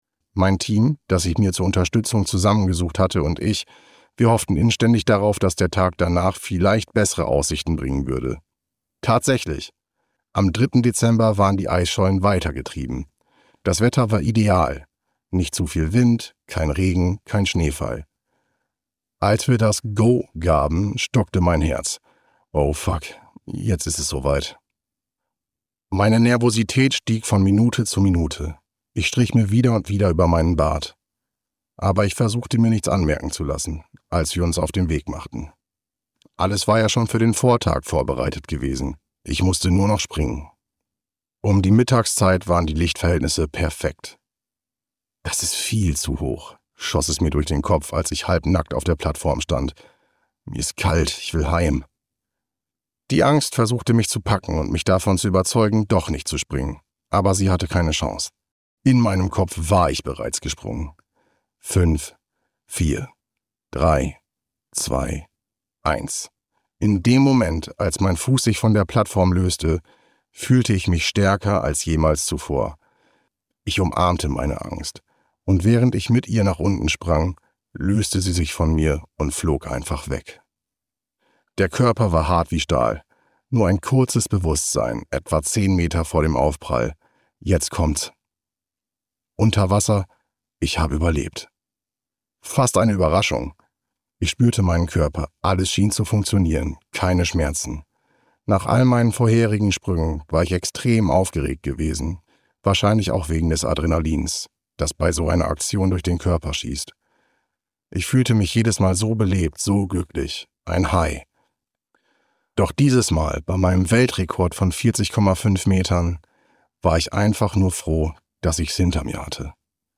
Audio knihaMein Leben als letzter Wikinger (DE)
Ukázka z knihy